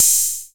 OPEN HAT.wav